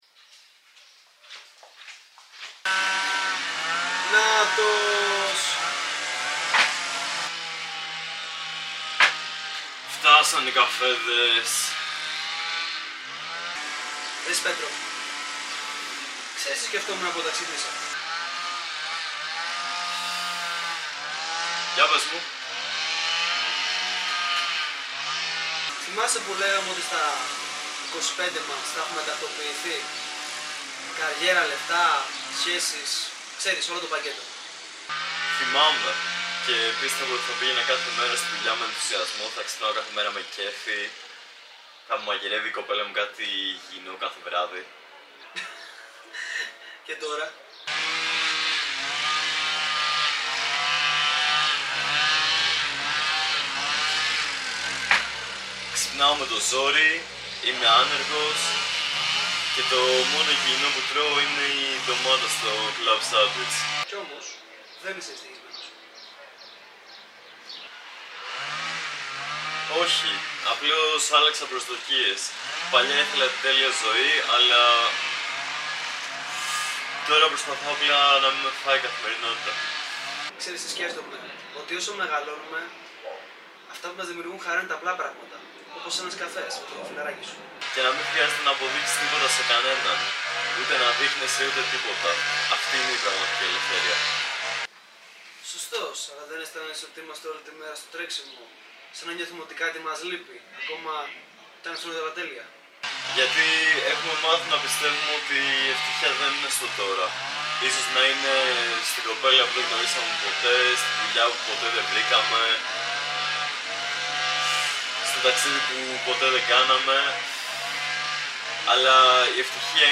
Background noise
It already is bad enough cause we couldn't use shotgun mics that day and had to use the camera audio. What I want removed is the incredibly annoying "VRRRRMMMM" that the bush cutting machine is making.